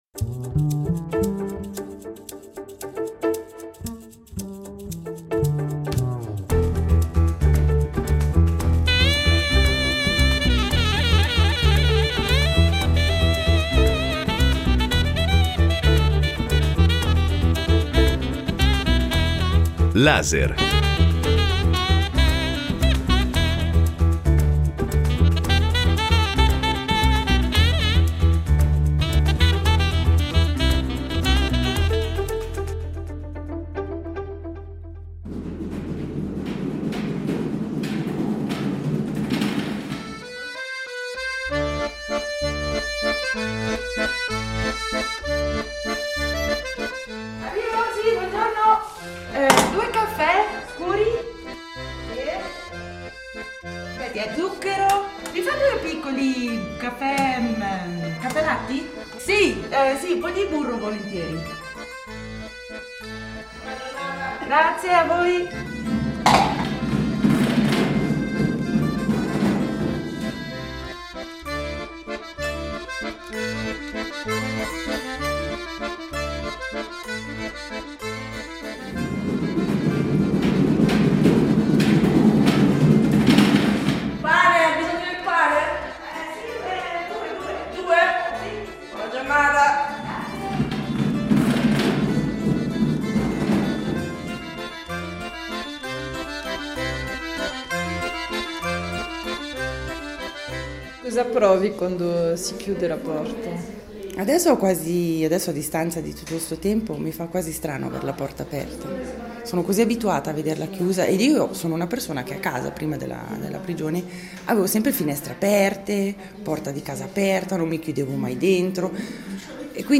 Sono le domande che hanno animato il corso di radio-giornalismo svolto con le donne detenute alla Farera. Un incrocio tra racconti, interviste e rappresentazioni. Con musica scelta dalle detenute e testi scritti da loro.